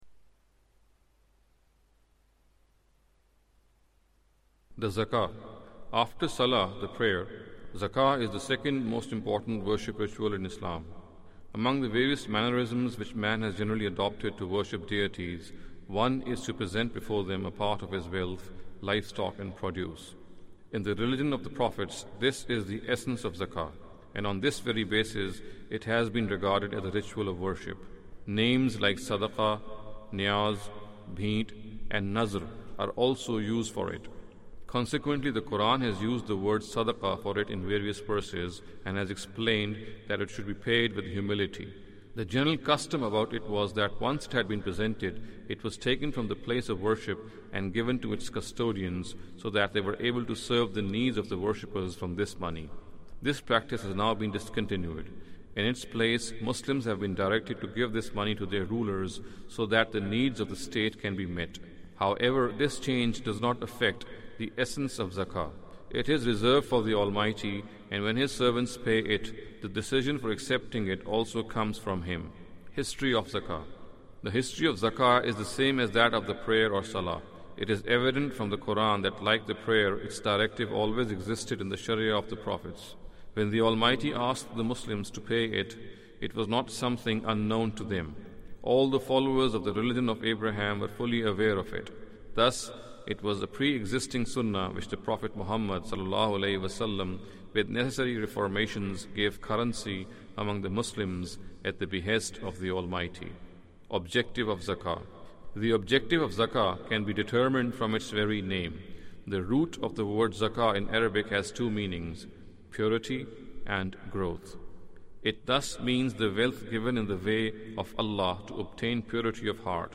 Audio book of English translation of Javed Ahmad Ghamidi's book "Islam a Concise Intro".